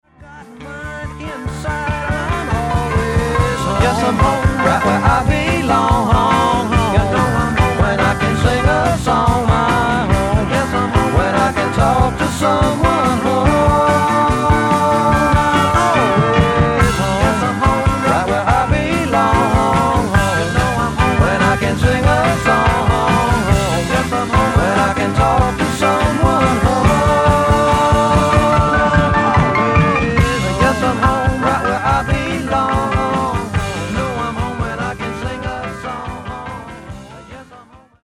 60年代からＮＹ/東海岸を拠点にそれぞれ活動していた、2人のシンガーと3人のインストゥルメンタリストからなる5人組。